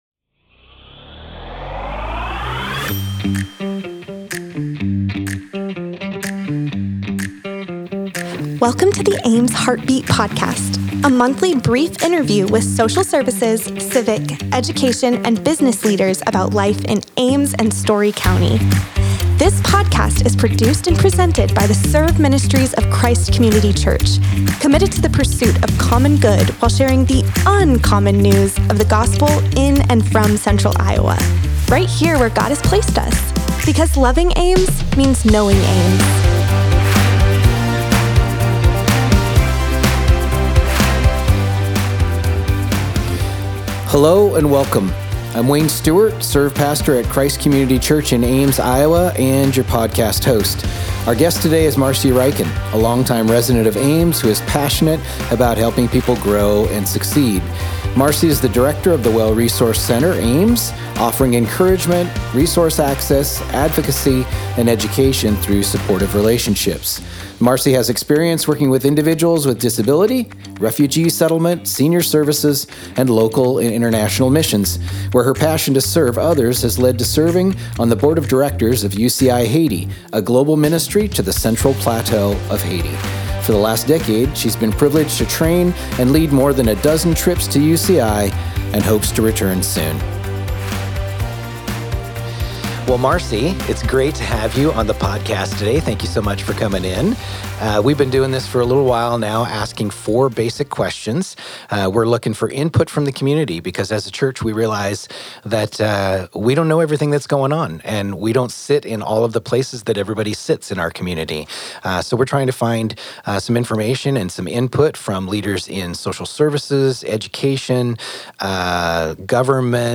The Ames Heartbeat Podcast features brief interviews with civic, education, social services, and business leaders in Ames and surrounding communities.